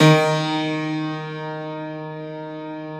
53a-pno07-D1.aif